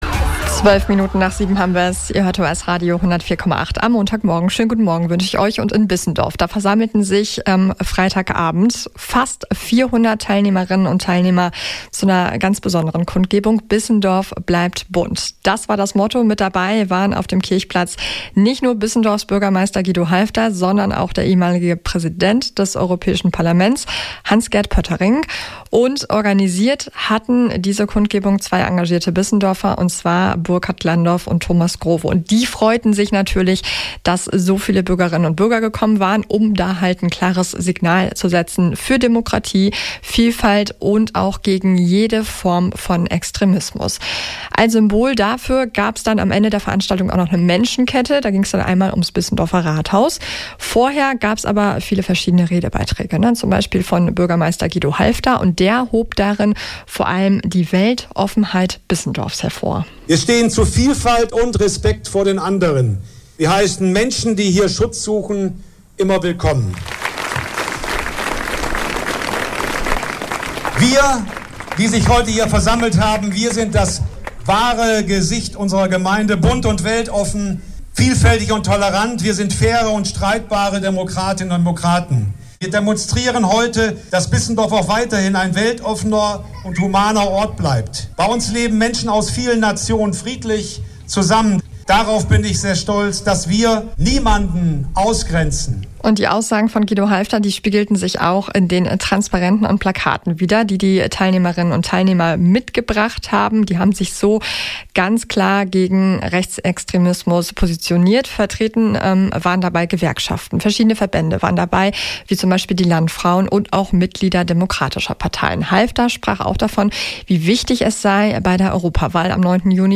Einen ausführlichen Bericht über die Demo gibt es hier: